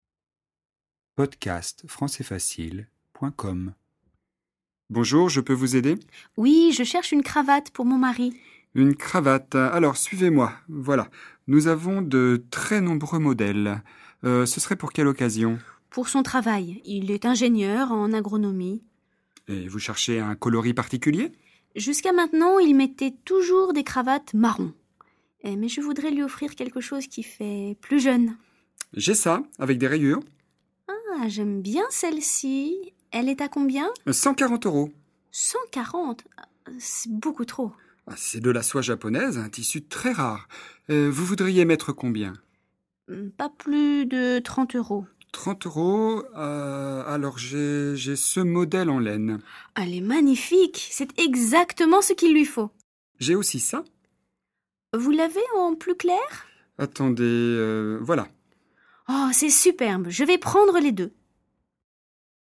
Dialogue FLE, niveau débutant/intermédiaire (A2) sur le thème d'une situation d'achat.